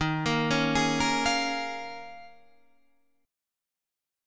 Eb9sus4 Chord